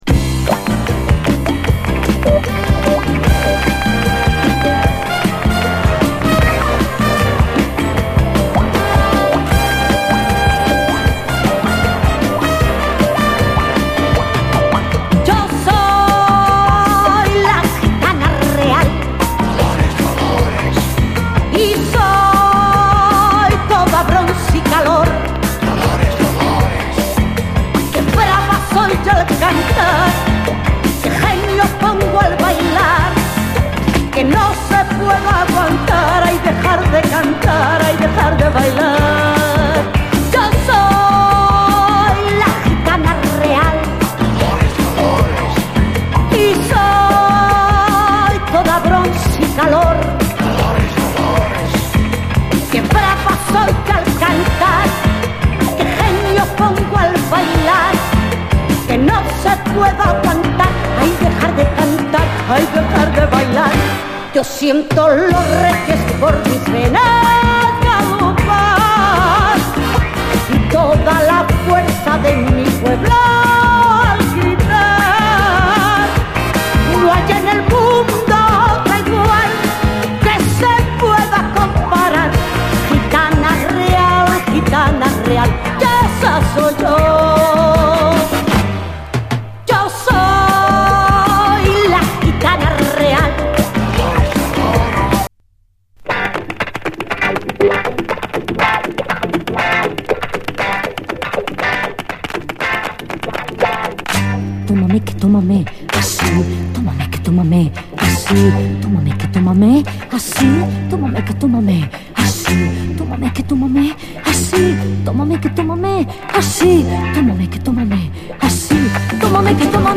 グルーヴィーなルンバ・フラメンカをコンパイルした、70’S当時のスペイン産コンピレーション盤！